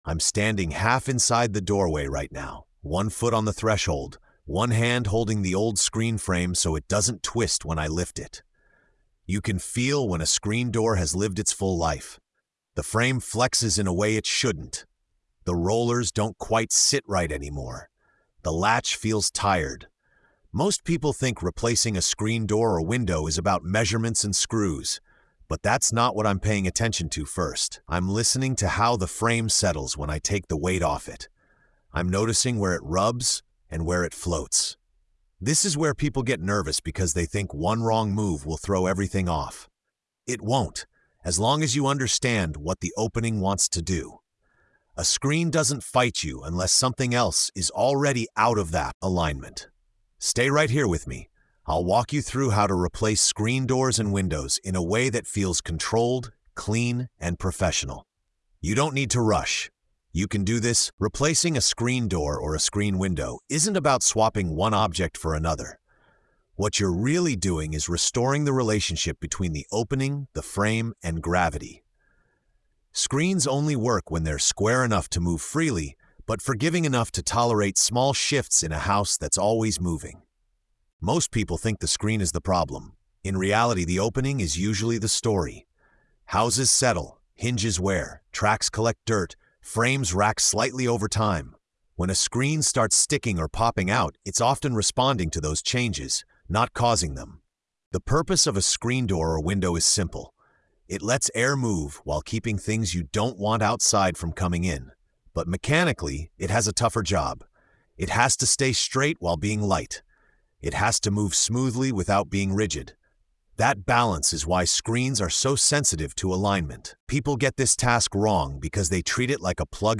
Through calm, first-person teaching and job-site insight, the episode shows how small misjudgments lead to binding doors, warped frames, and constant frustration, while patience and awareness lead to smooth, professional results. The emotional tone is steady, reassuring, and empowering, reminding the listener that confidence comes from understanding, not force.